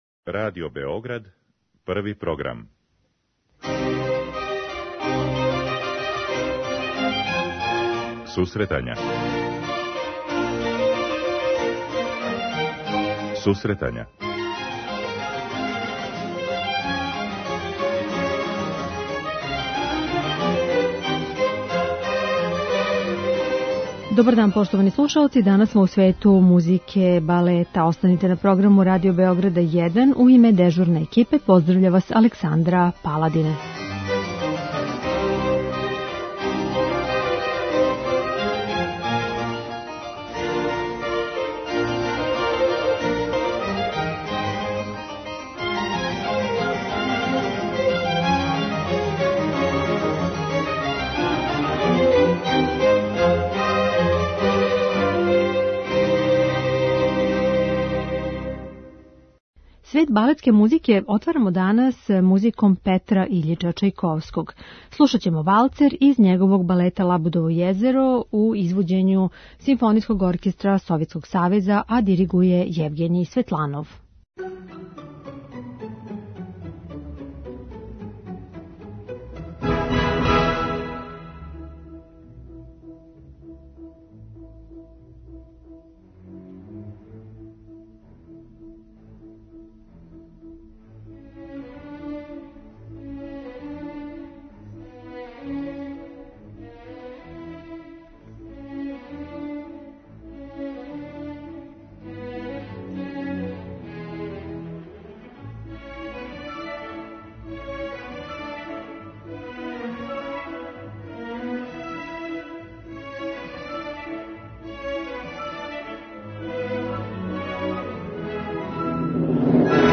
У данашњој емисији слушаоци ће бити у прилици да чују неке од најлепших фрагмената балетске музике Петра Иљича Чајковског, Леа Делиба и Лудвига Минкуса.